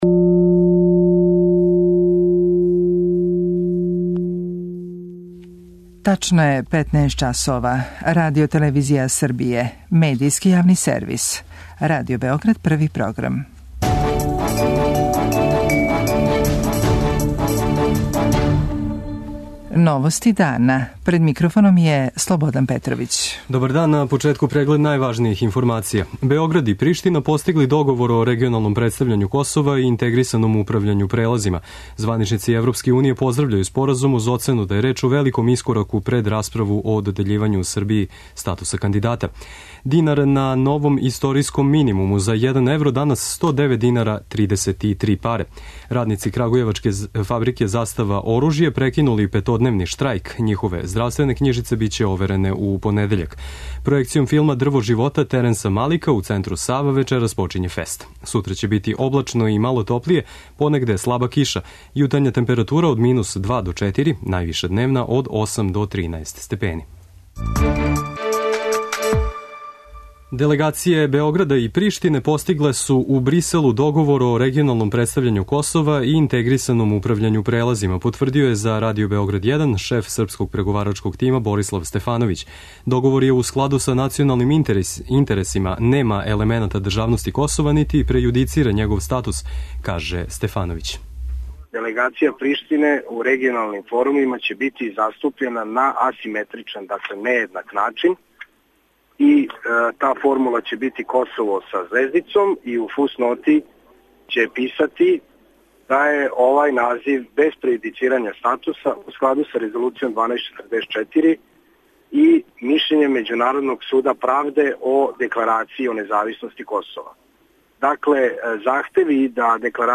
Преговарачки тимови Београда и Приштине постигли су споразум о регионалном представљању Косова и интегрисаној контроли прелаза на административним линијама – каже за Радио Београд 1 шеф београдског преговарачког тима Борислав Стефановић.
преузми : 15.30 MB Новости дана Autor: Радио Београд 1 “Новости дана”, централна информативна емисија Првог програма Радио Београда емитује се од јесени 1958. године.